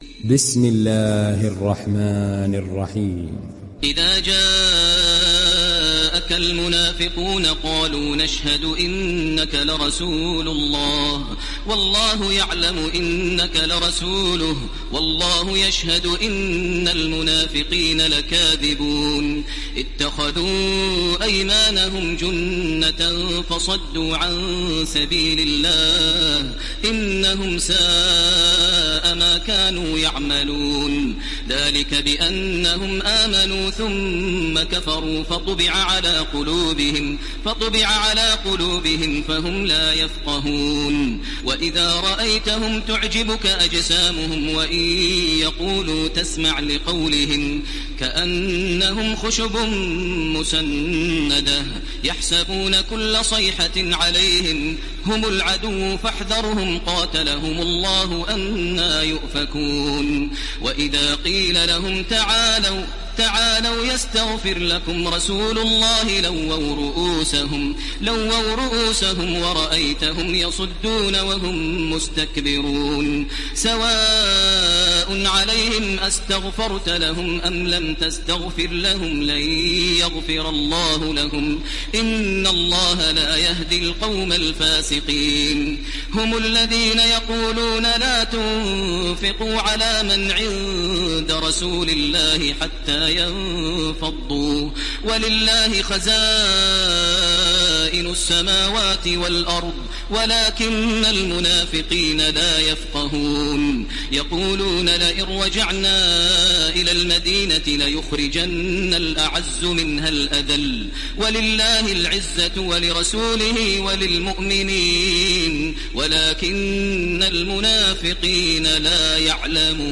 Download Surat Al Munafiqun Taraweeh Makkah 1430